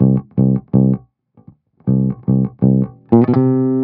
08 Bass Loop C.wav